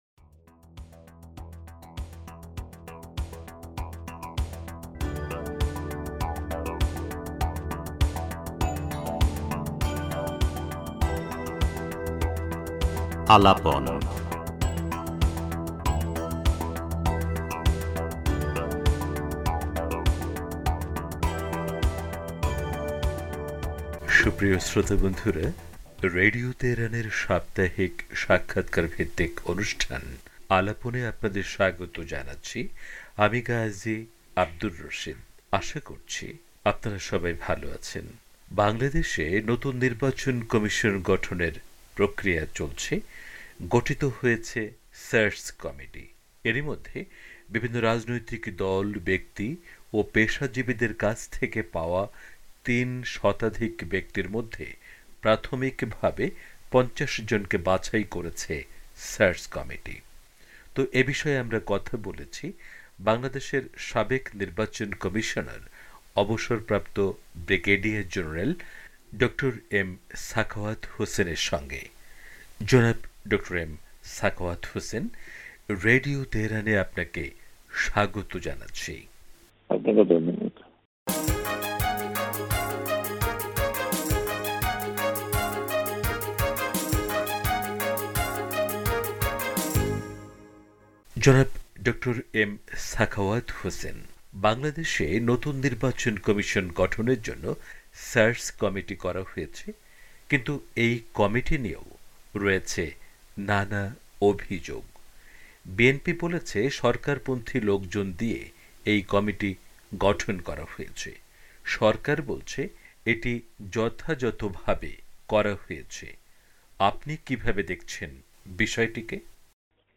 রেডিও তেহরানকে দেয়া বিশেষ সাক্ষাৎকারে একথা বলেছেন সাবেক নির্বাচন কমিশনার, অব. ব্রিগেডিয়ার জেনারেল ড. এম সাখাওয়াত হোসেন।